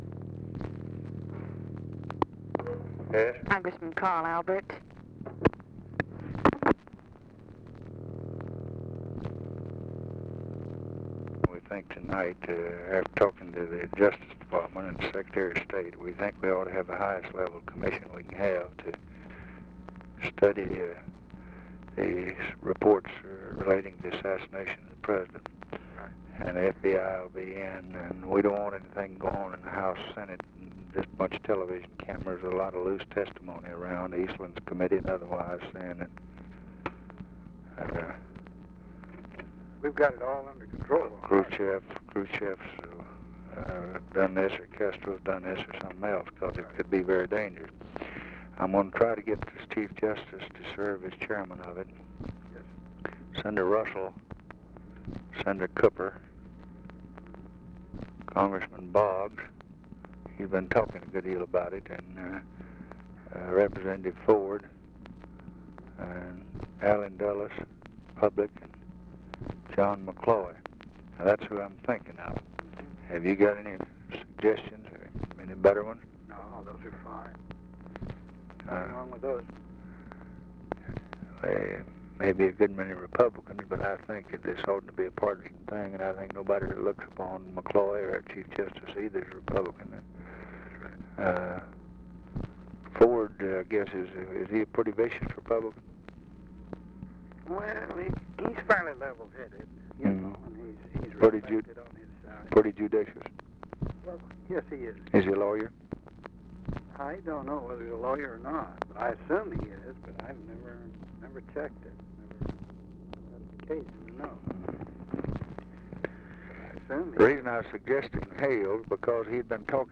Conversation with CARL ALBERT, November 29, 1963
Secret White House Tapes